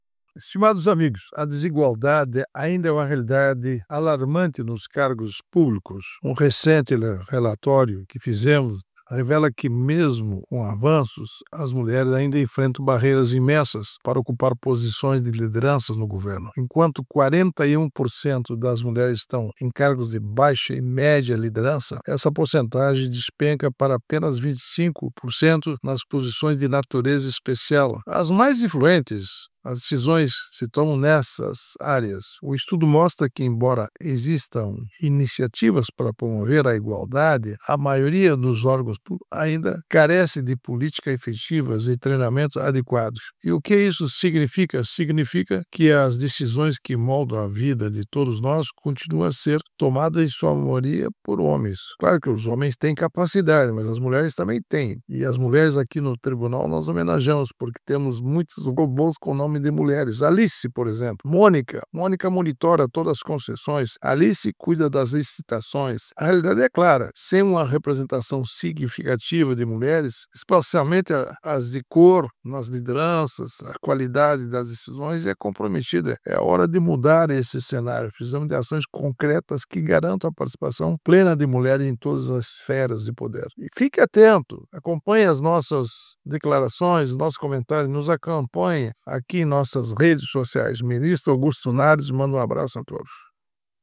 Comentário do Ministro do TCU, Augusto Nardes.